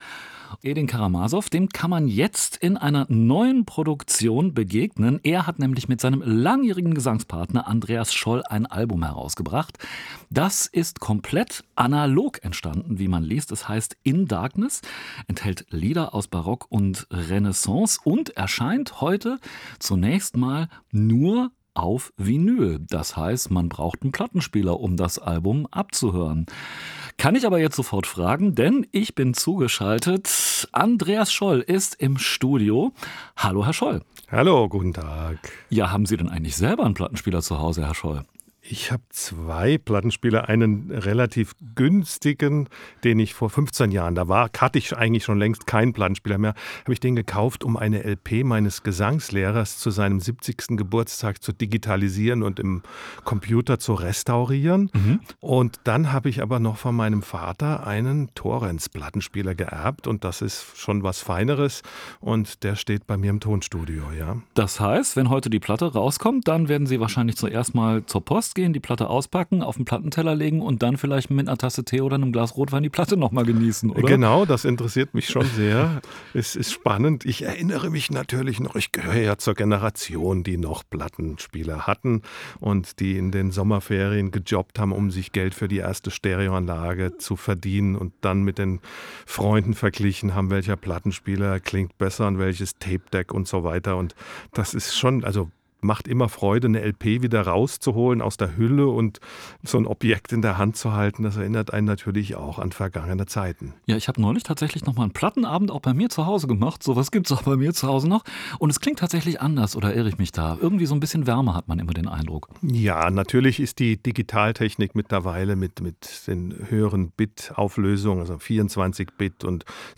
Musikgespräch
Interview mit